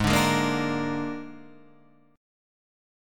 G#sus2b5 chord